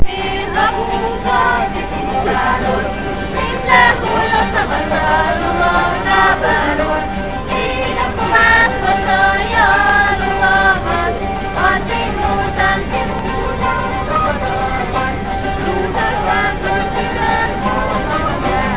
Un grupo de niños interpretando música tradicional húngara.